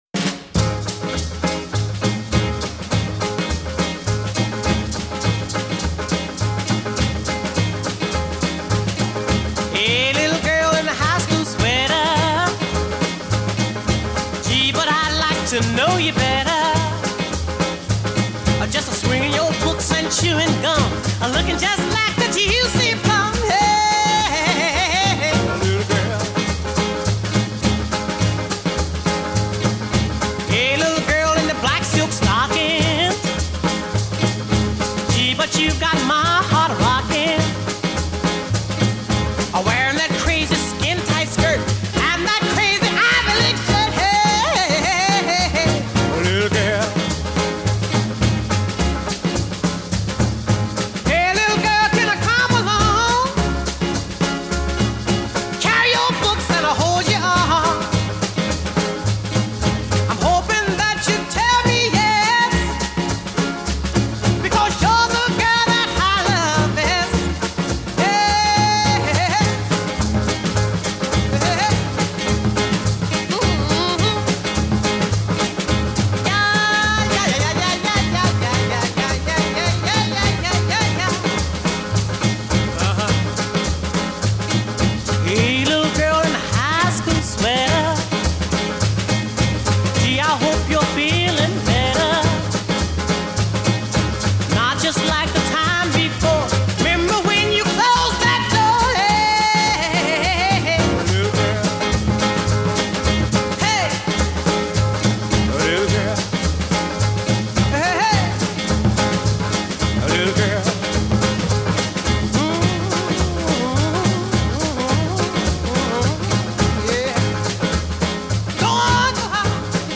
Check out the Bo Diddley background beat.